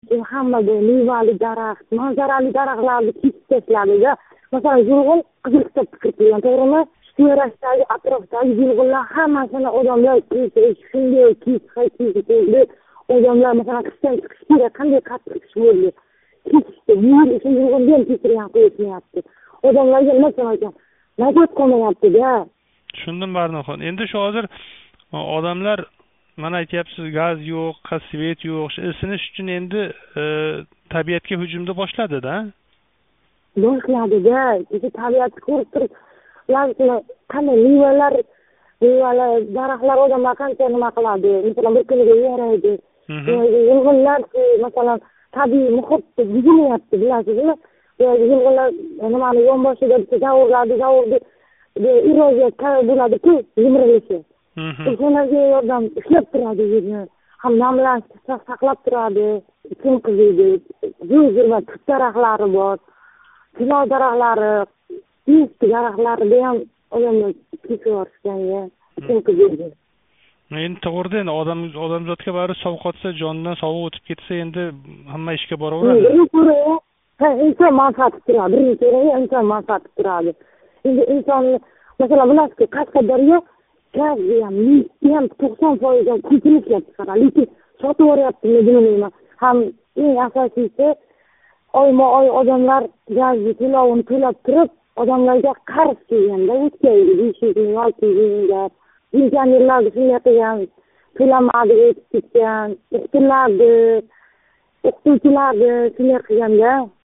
Нишонлик суҳбатдош билан суҳбат